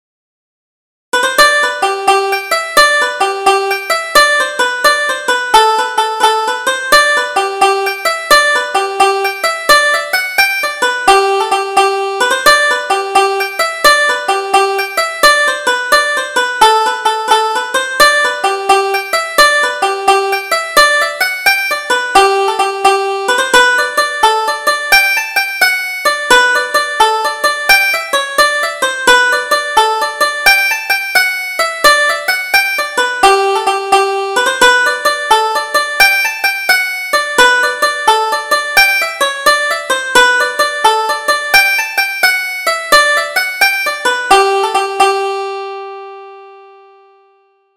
Double Jig: Owen Malone